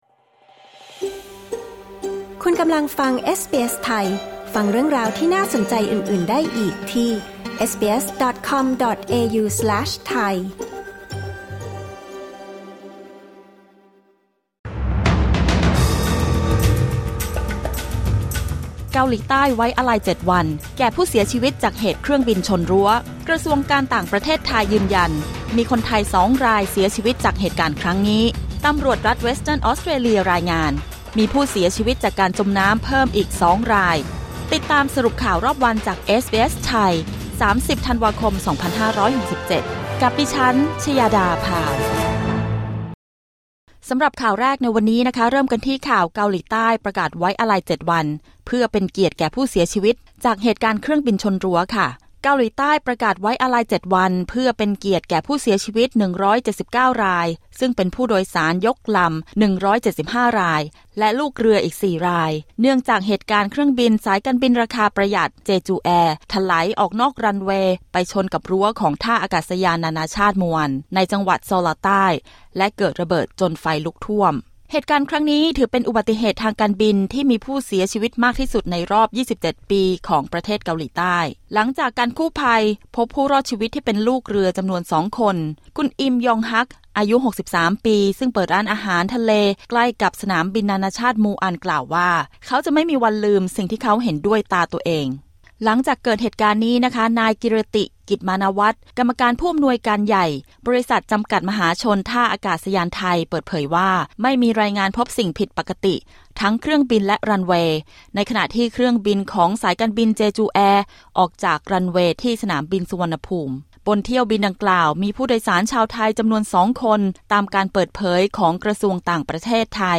สรุปข่าวรอบวัน 30 ธันวาคม 2567